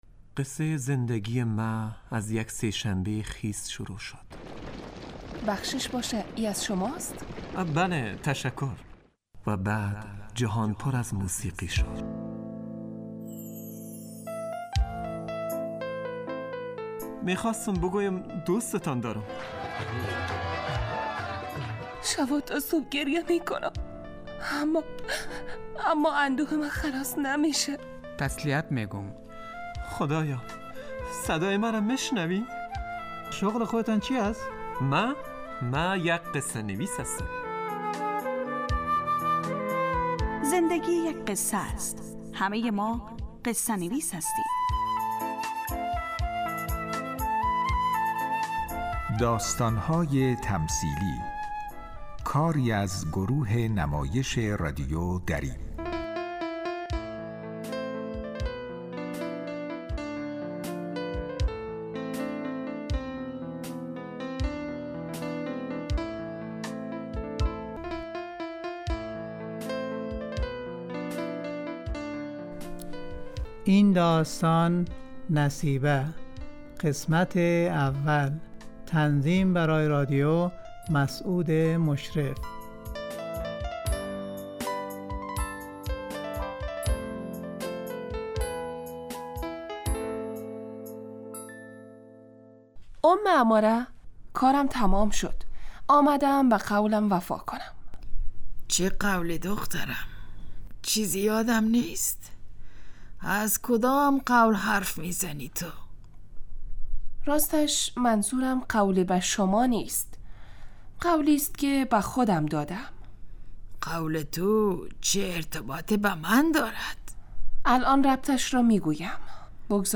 داستانهای تمثیلی نمایش 15 دقیقه ای هست که از شنبه تا پنج شنبه ساعت 03:20 عصربه وقت افغانستان پخش می شود.